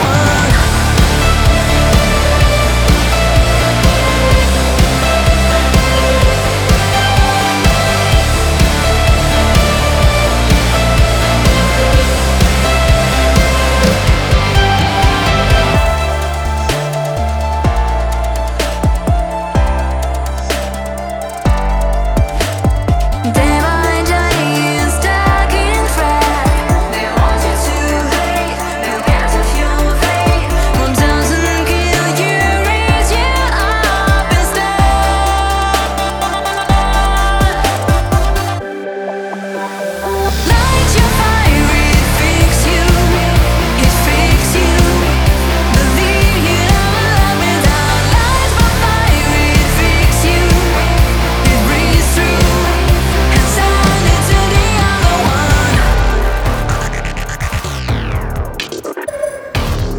Зацените сведение Rock Metal Alternative
А то там она прям вокал давила в этом месте.